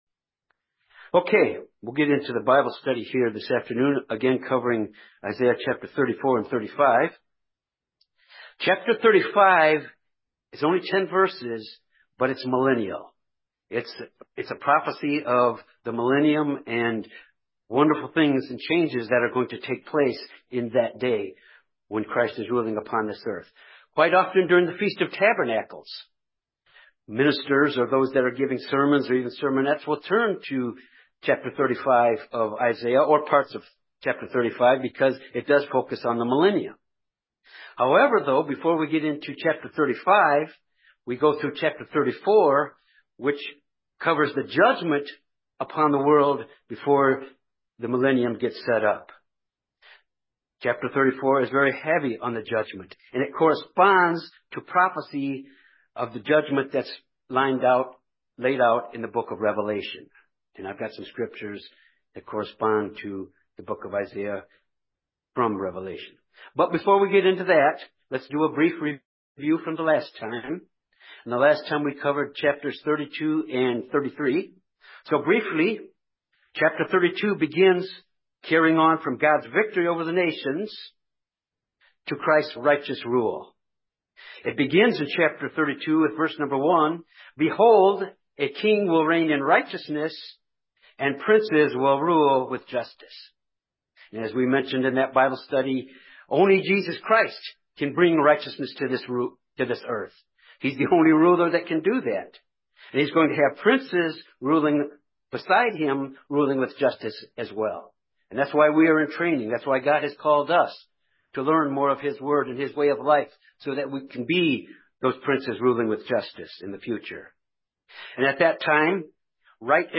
This Bible study of Isaiah covers chapters 34 and 35. Chapter 35 is millennial...but that will not happen until judgment upon the world happens, which is described in chapter 34.